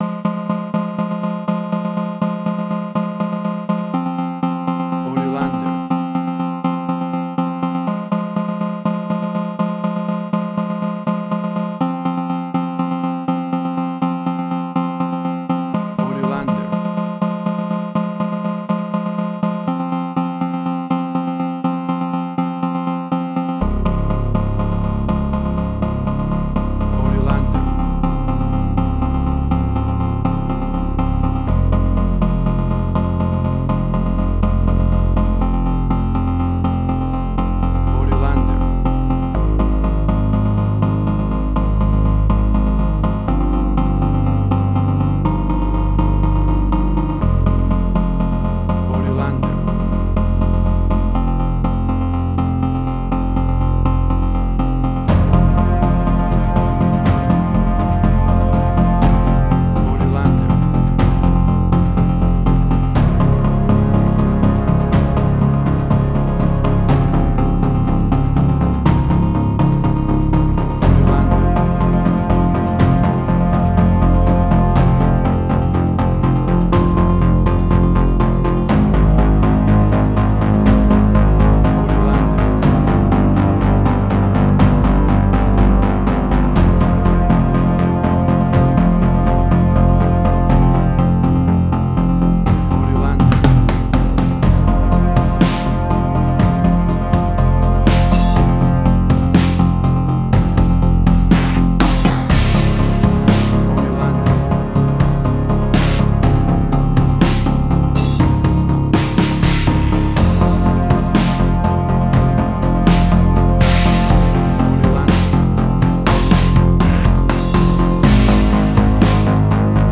WAV Sample Rate: 16-Bit stereo, 44.1 kHz
Tempo (BPM): 122